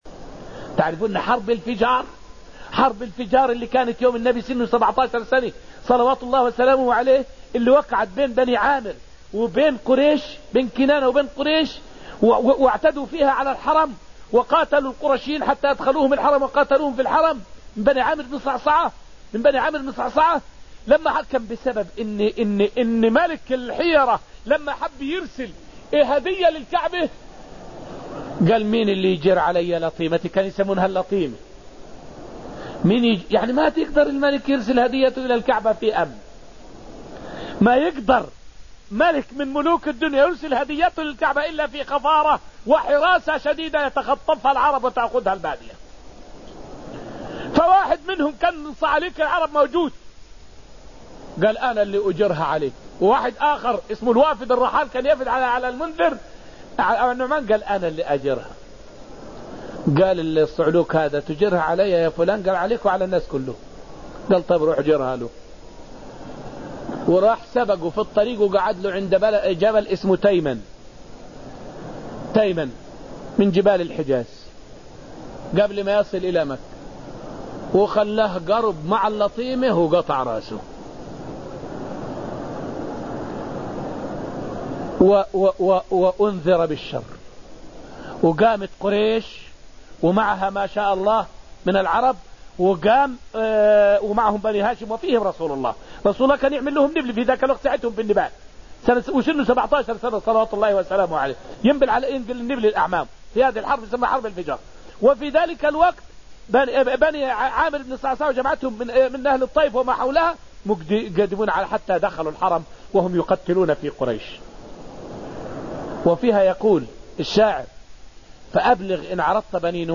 فائدة من الدرس الحادي عشر من دروس تفسير سورة المجادلة والتي ألقيت في المسجد النبوي الشريف حول من قام لله وإن هُزِم فهو منتصر.